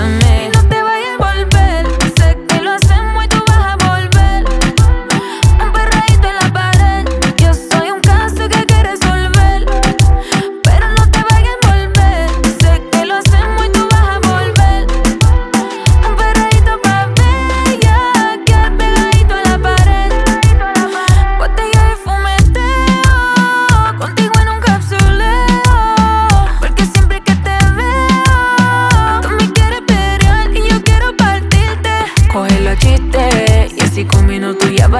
# Pop in Spanish